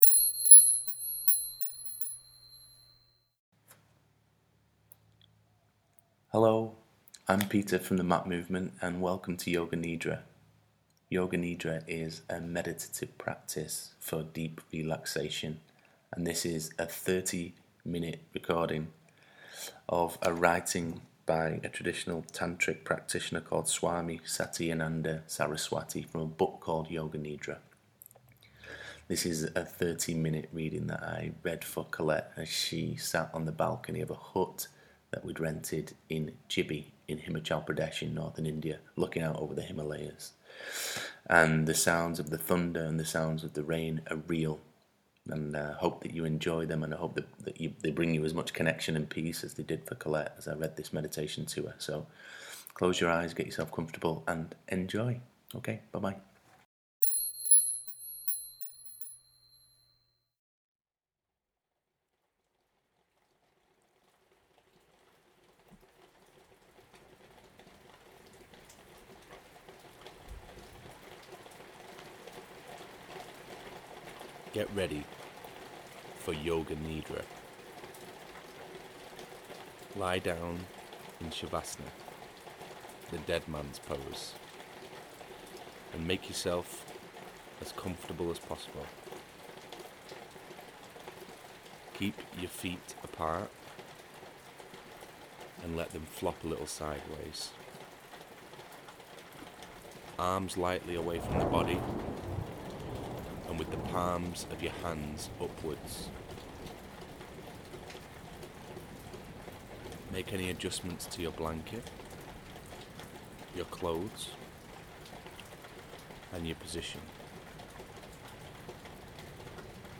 Guided Meditations
yoga-nidra.mp3